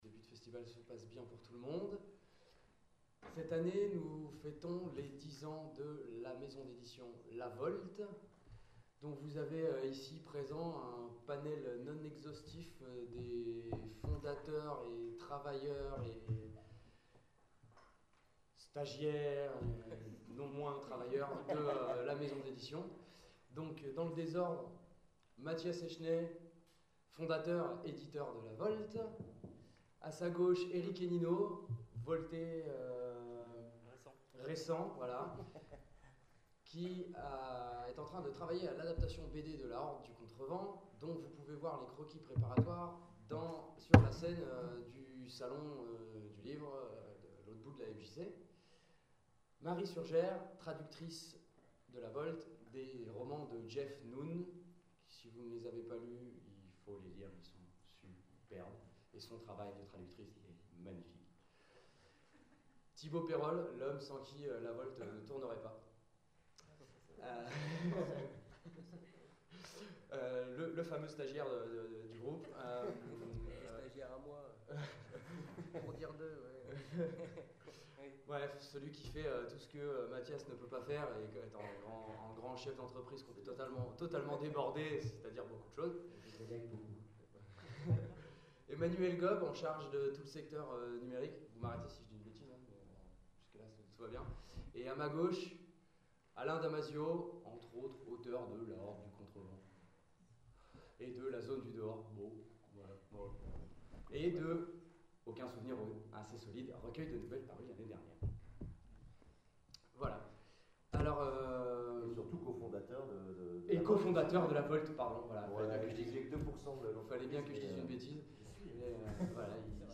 Les intergalactiques 2014 : conférence La VOLTE a 10 ans : le fonctionnement atypique d’une maison d’édition hors du commun